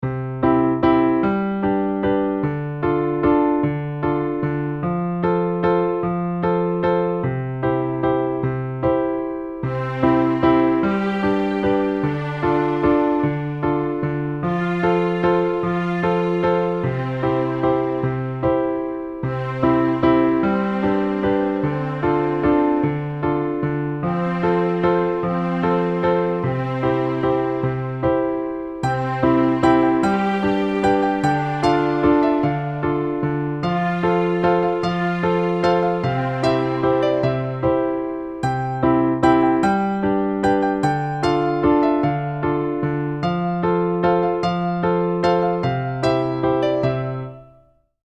Música orquestal (escena de dicha